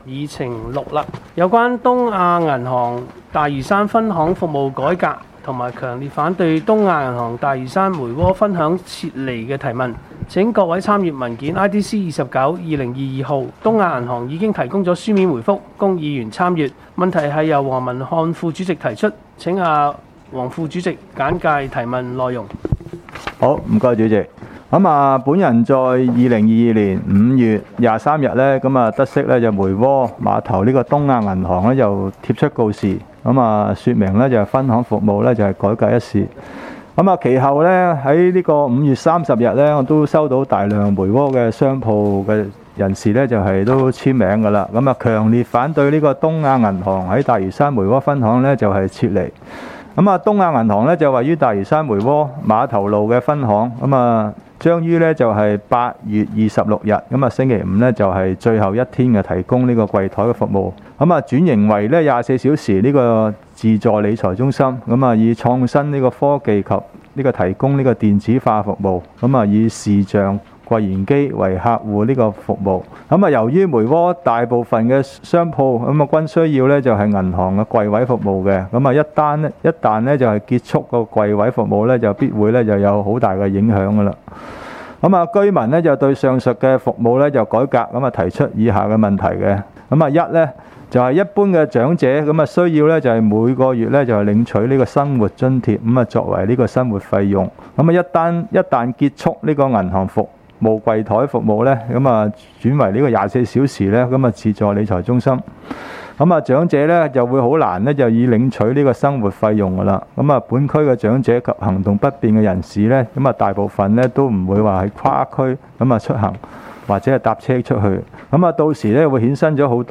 區議會大會的錄音記錄
離島區議會會議室